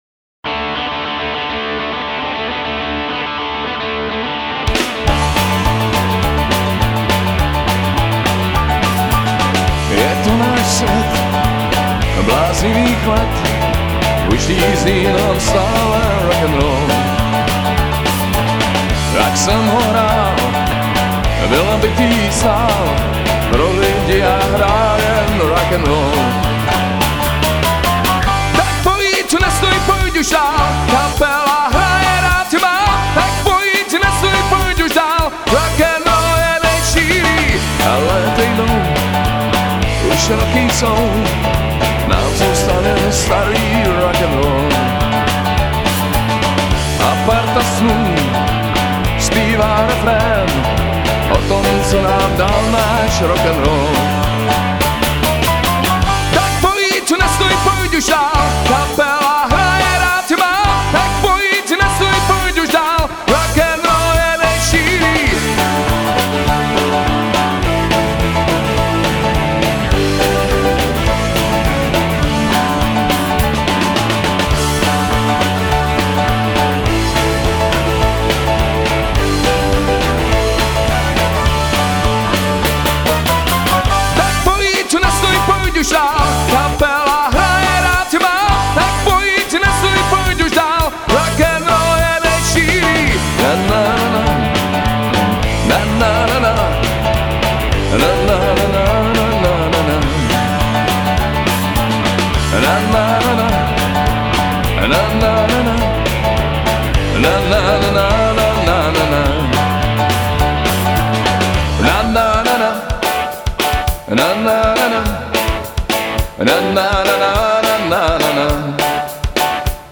zpěv
kytary, vokály
baskytara
bicí
klávesové nástroje